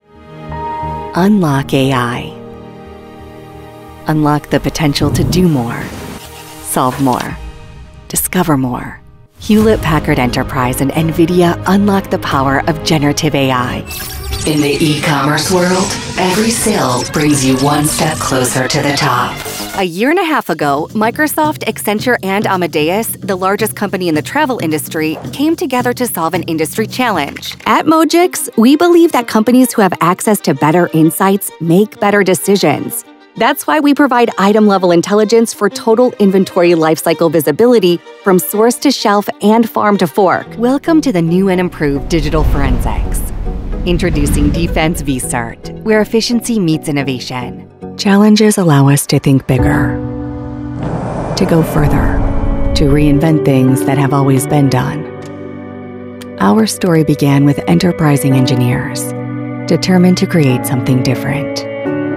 Trusted by global brands, she delivers professional, friendly, and natural reads with fast turnaround for international clients.
Explainer Videos
Are you looking for a professional North American woman voice over talent?
Studiobricks recording booth
Neumann U87
Middle-Aged
Young Adult